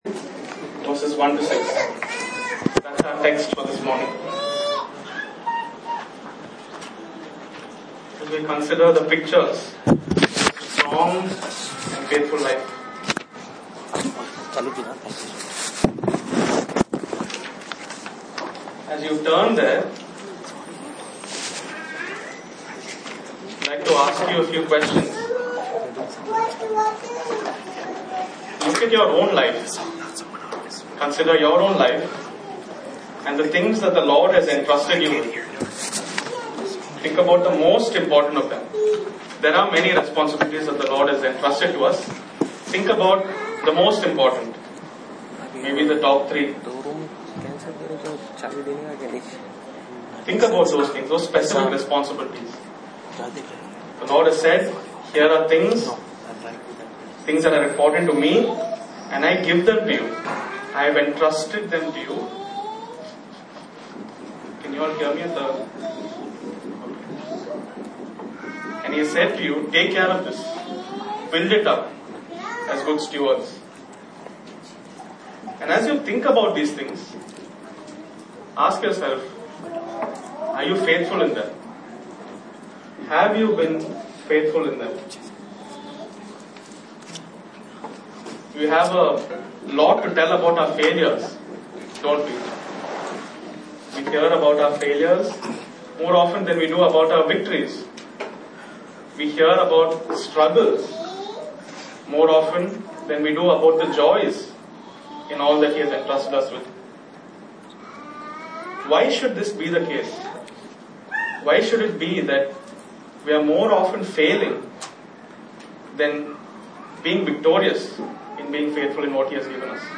Passage: 2 Tim 2:1-6 Service Type: Sunday Morning